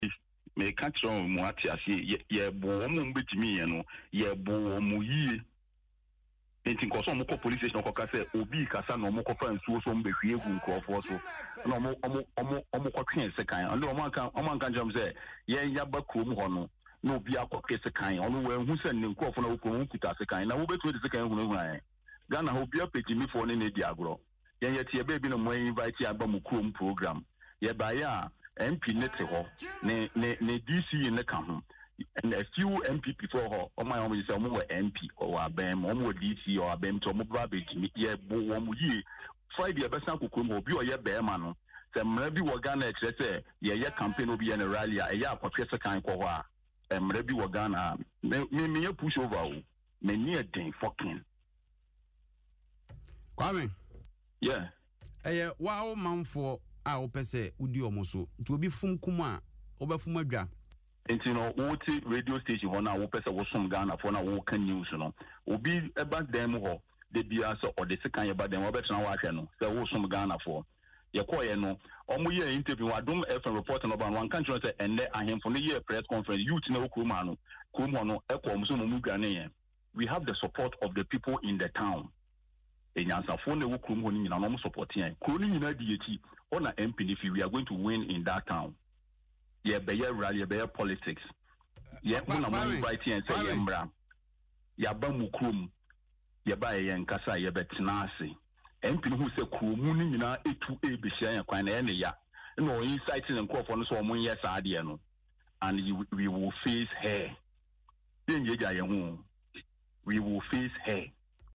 In an interview on Adom FM’s Naket Kasiebo, A-Plus neither admitted nor denied pulling the pistol at the ceremony.